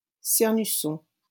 Cernusson (French pronunciation: [sɛʁnysɔ̃]